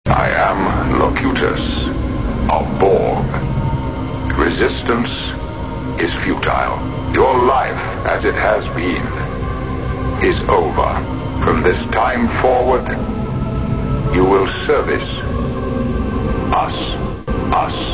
Star Trek Sounds
Englisch Locutus redet mit Com.Riker